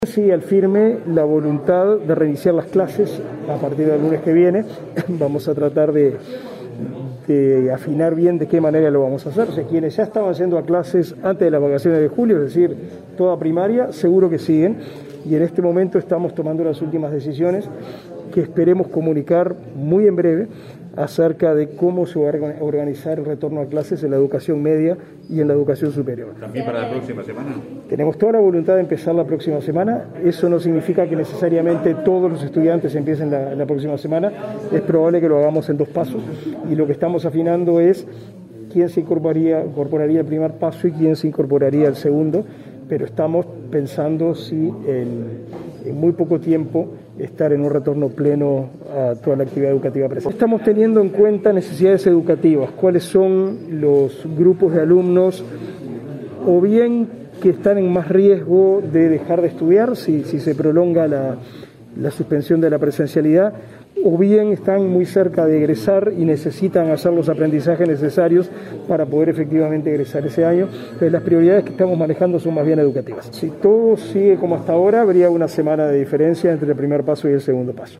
Participó en la jornada de este lunes en una donación de dispositivos para personas con discapacidad visual por parte de la Embajada de Israel que tuvo lugar en el Ministerio de Desarrollo Social (Mides).